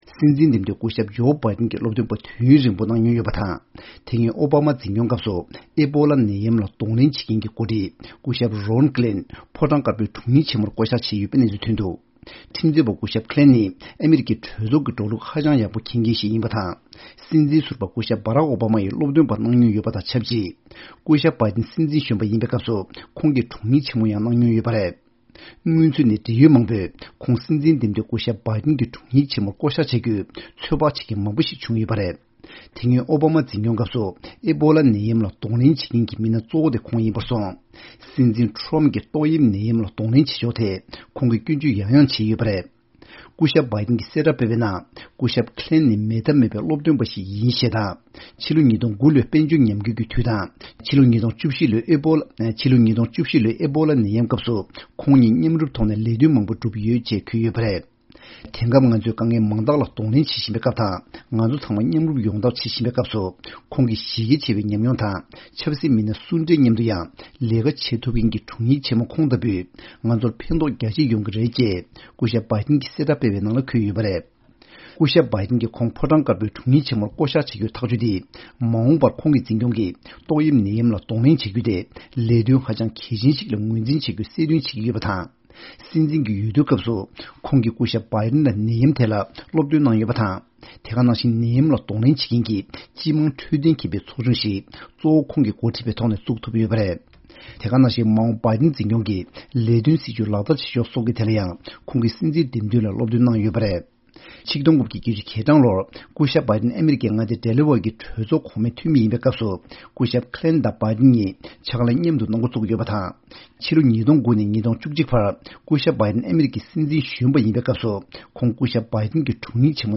གནས་ཚུལ་སྙན་སྒྲོན